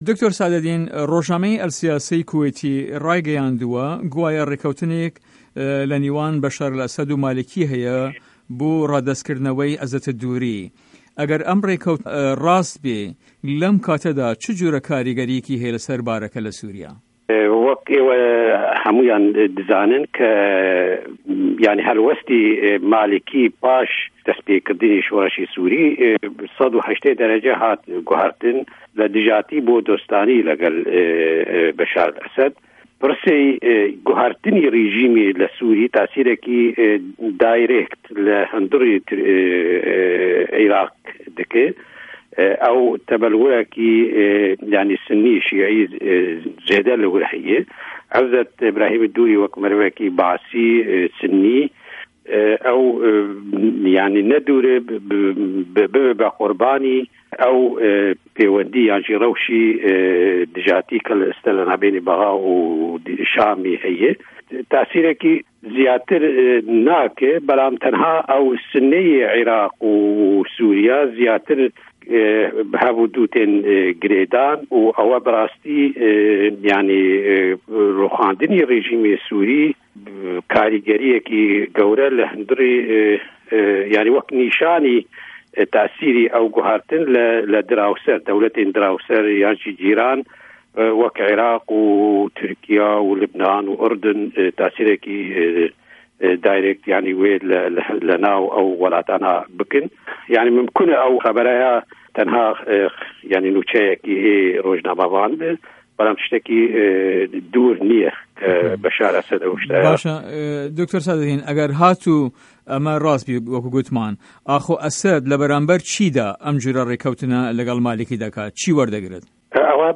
وتو وێژ